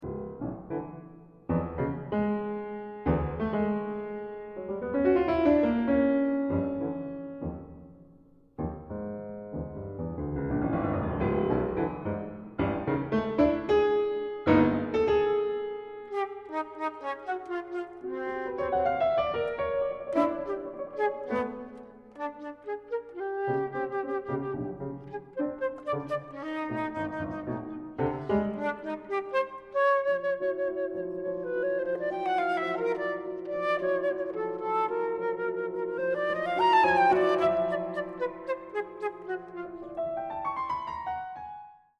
flute